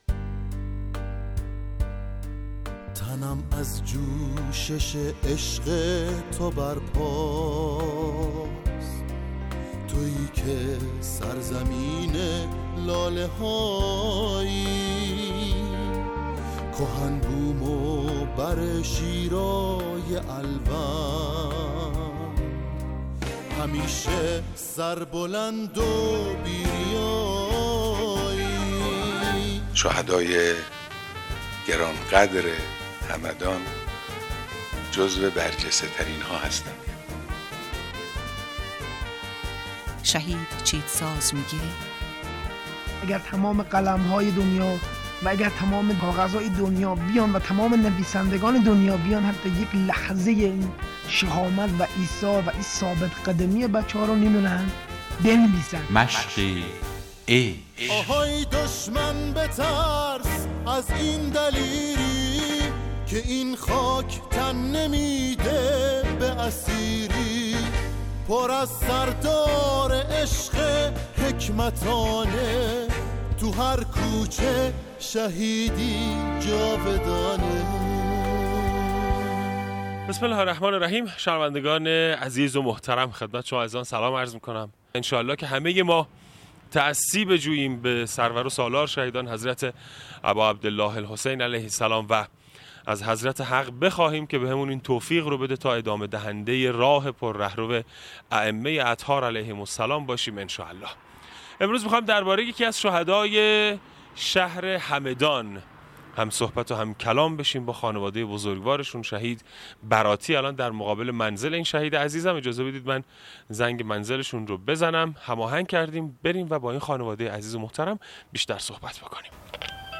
مصاحبه با خانواده شهید